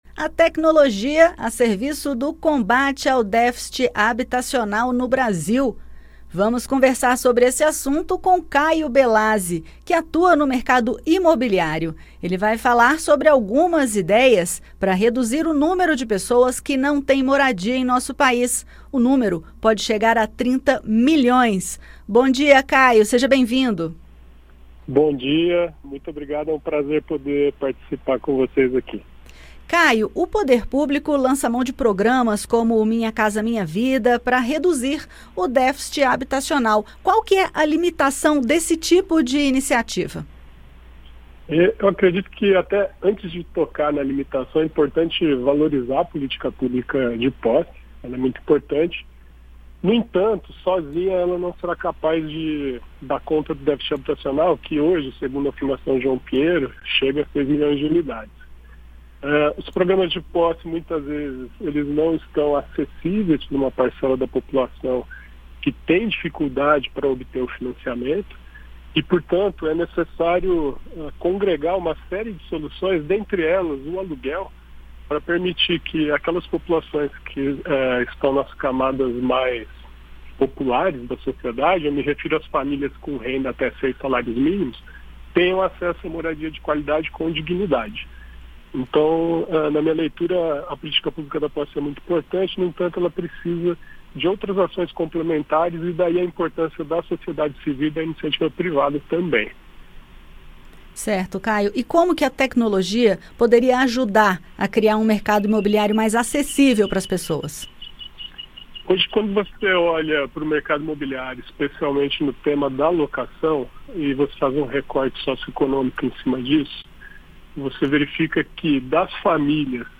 Programa diário com reportagens, entrevistas e prestação de serviços
Especialista em mercado imobiliário fala sobre o déficit habitacional e ideias para reduzir o problema